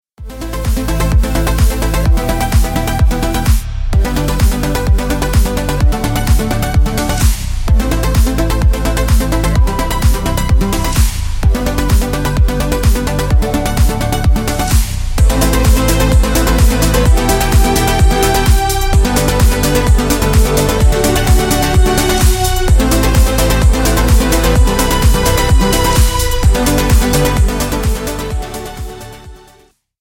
Рингтоны Без Слов
Рингтоны Ремиксы » # Рингтоны Электроника